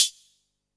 Closed Hats
pcp_hihat09.wav